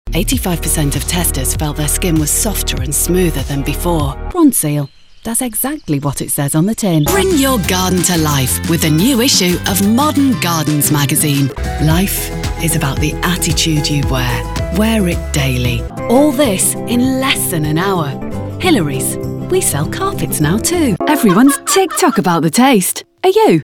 Anglais (Britannique)
Distinctive, Polyvalente, Amicale